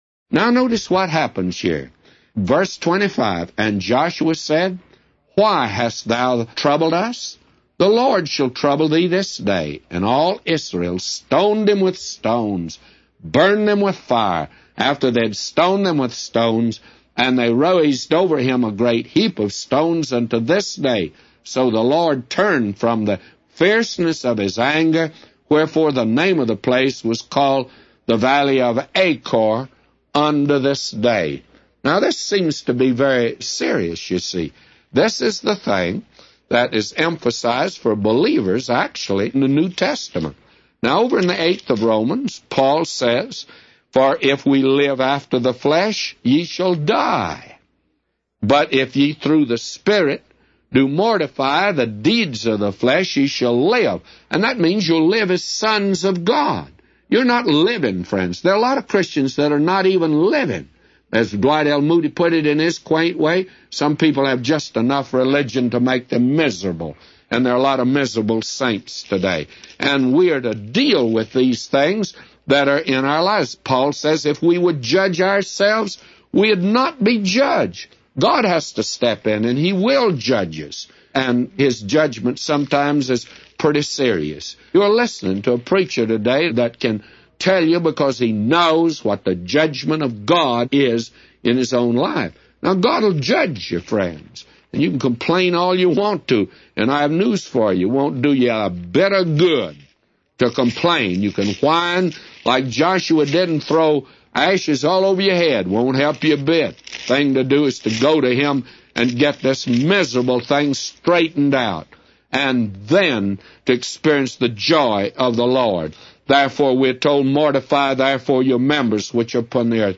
A Commentary By J Vernon MCgee For Joshua 7:19-999